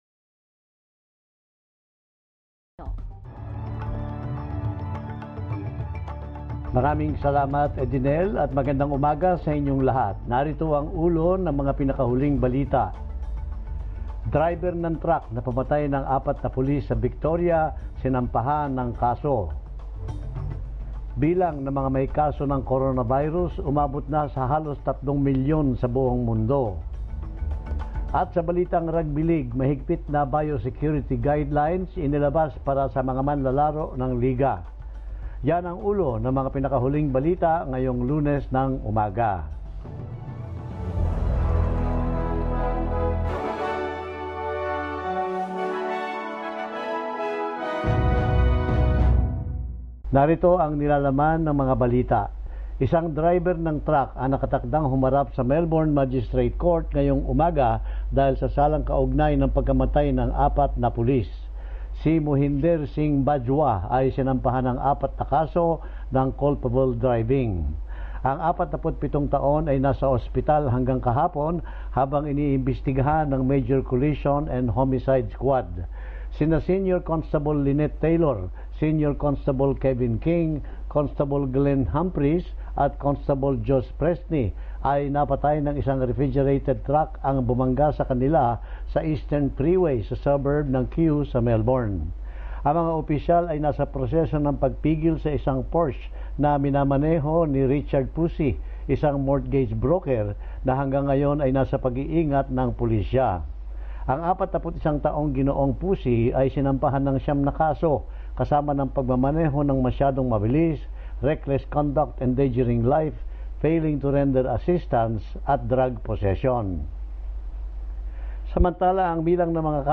SBS News in Filipino, Wednesday 27 April